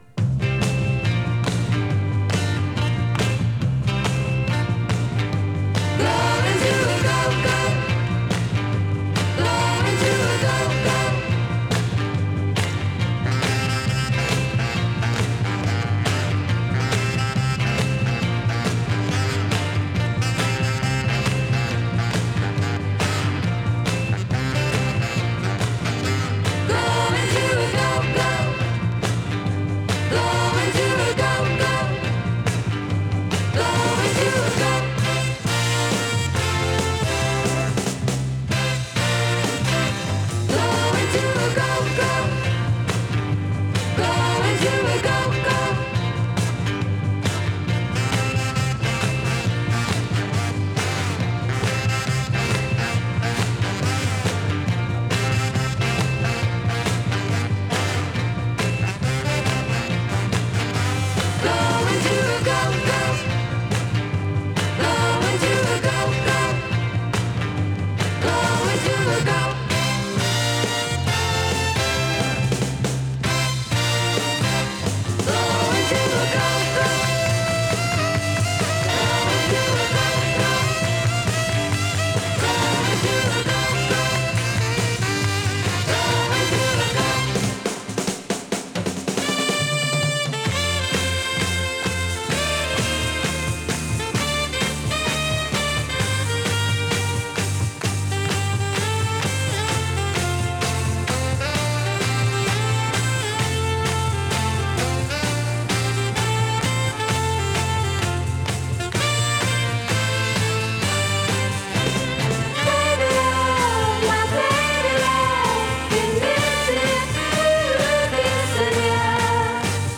シャープな演奏に華やかな女性コーラスが花を添えたダンス・アルバムです！